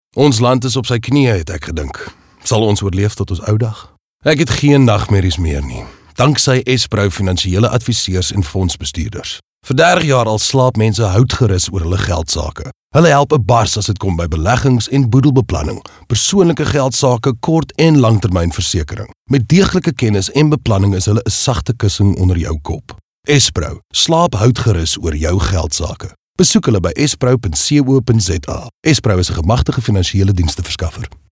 authoritative, Deep, raspy